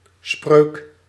Ääntäminen
US : IPA : [ˈspɛɫ]